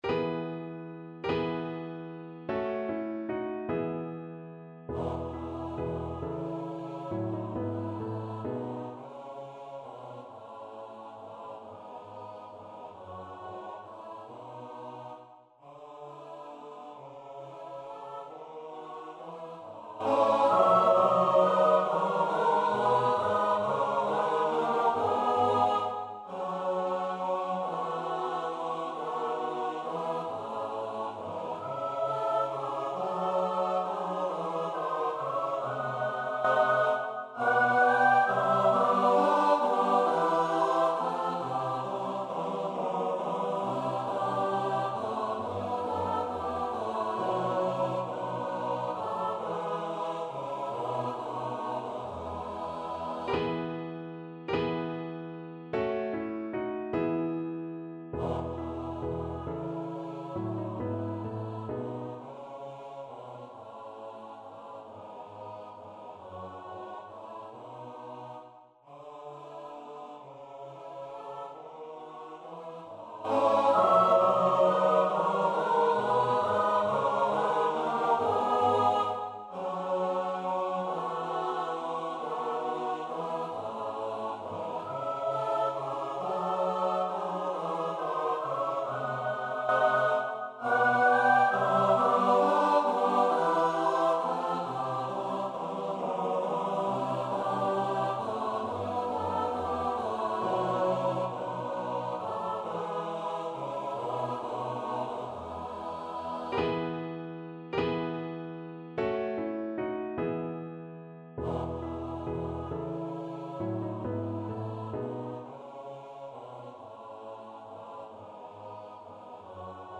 Voice
E major (Sounding Pitch) (View more E major Music for Voice )
3/8 (View more 3/8 Music)
Allegretto (. = 50)
Classical (View more Classical Voice Music)